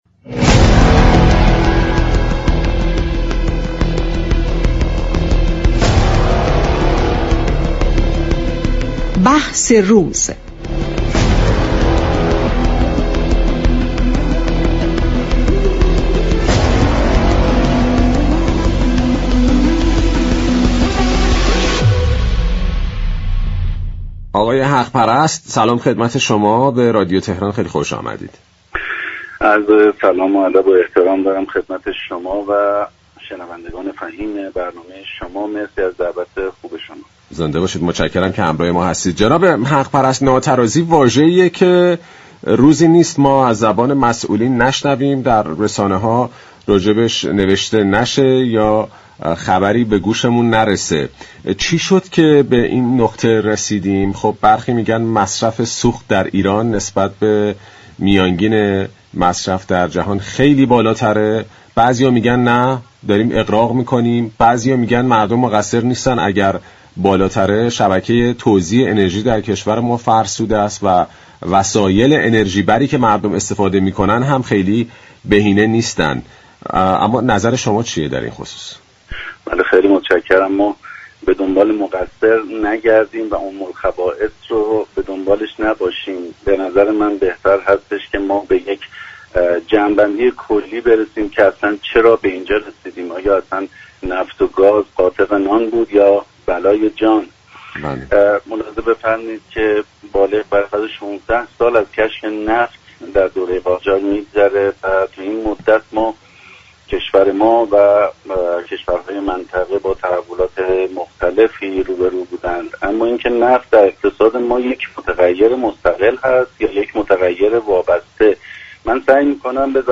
كارشناس اقتصادی در گفت و گو با «بازار تهران»